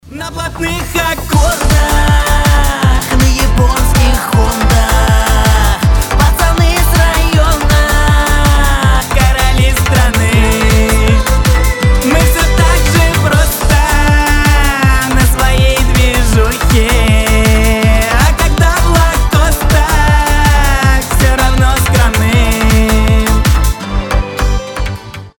• Качество: 320, Stereo
веселые
пацанские